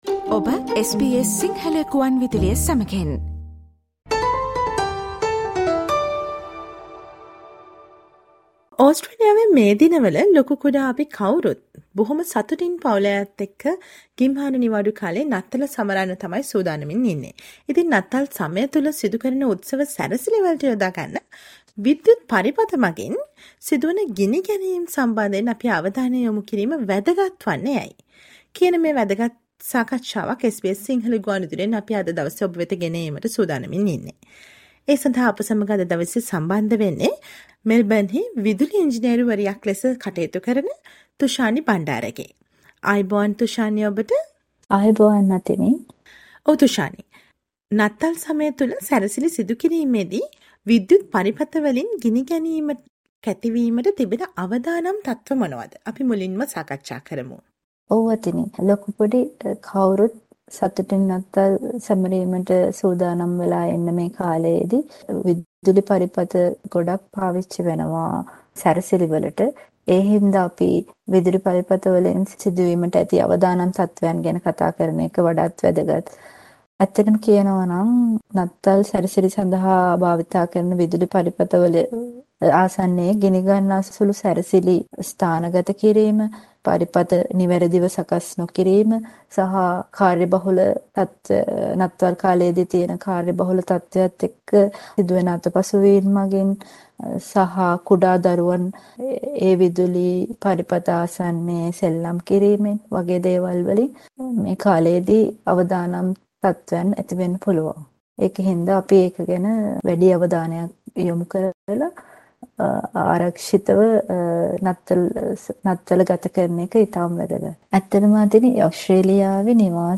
SBS Sinhala